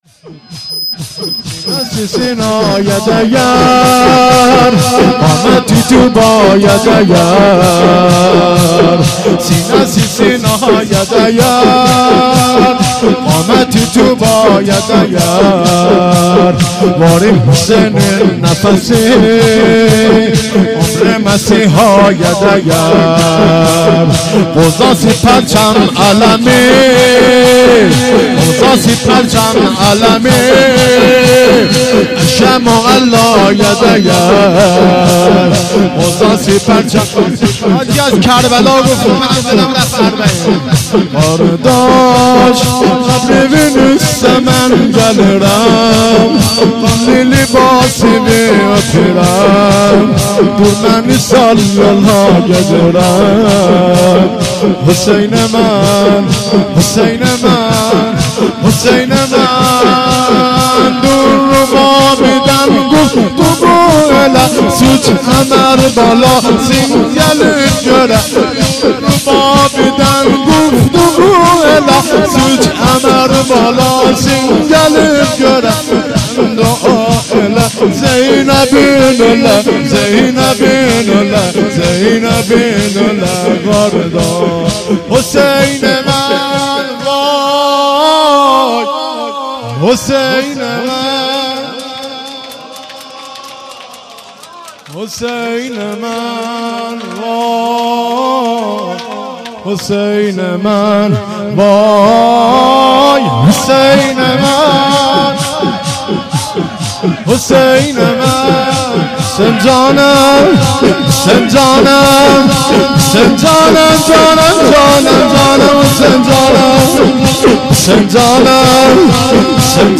شور
جلسه هفتگی